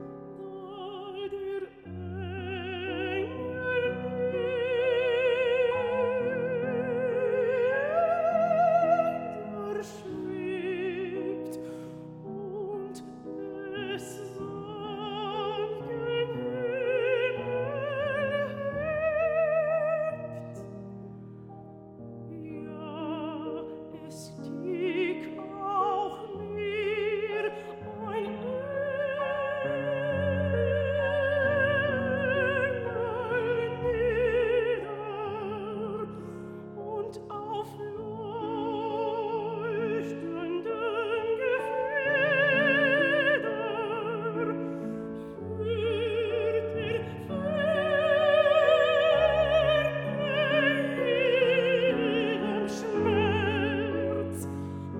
一場令人難忘的音樂會。
與生俱來且年輕的音質與體力，
使這位演唱家能夠充分控制、並相當細緻的處理每段語句與表情。
在2000年伊莉莎白女王音樂大賽的獲獎音樂會實況，